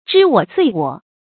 知我罪我 注音： ㄓㄧ ㄨㄛˇ ㄗㄨㄟˋ ㄨㄛˇ 讀音讀法： 意思解釋： 形容別人對自己的毀譽。